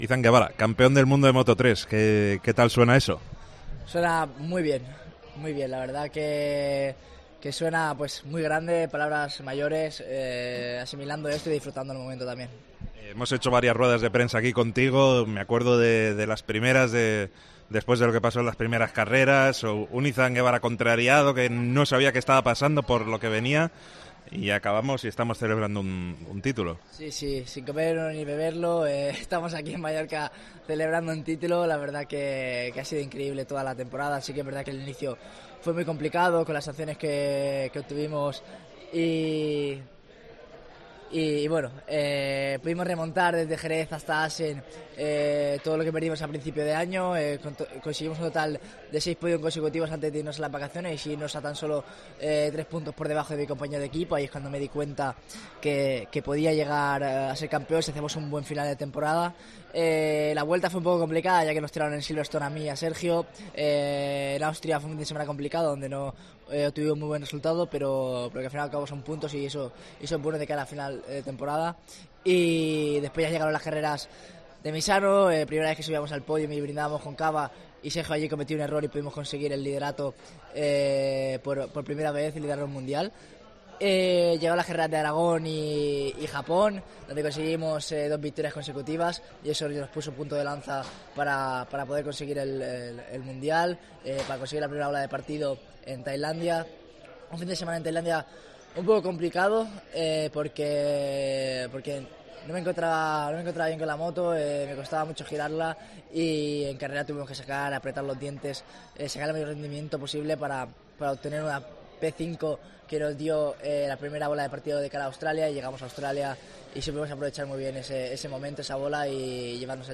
El flamante campeón de Moto3 repasa la temporada en Deportes Cope Baleares justo antes del último gran premio en Valencia y de su salto el año que viene a Moto2